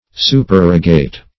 Supererogate \Su`per*er"o*gate\, v. i. [imp. & p. p.